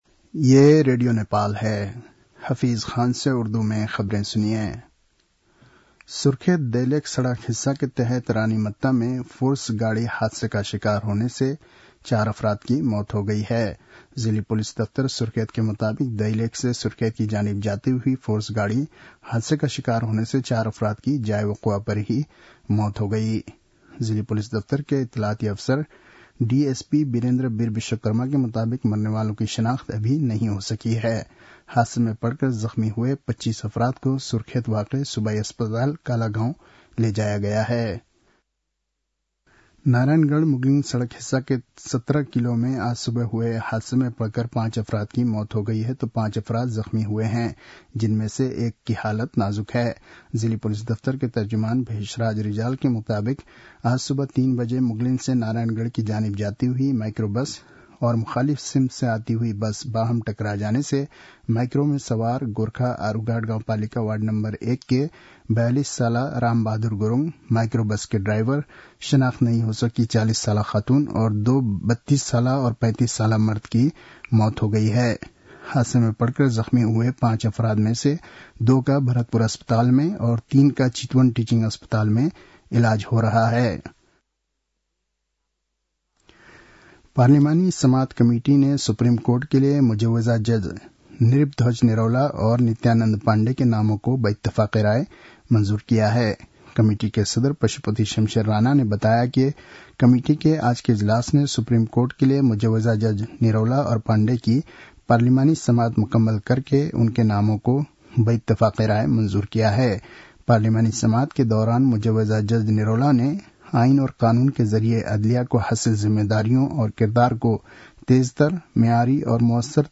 An online outlet of Nepal's national radio broadcaster
उर्दु भाषामा समाचार : २४ कार्तिक , २०८१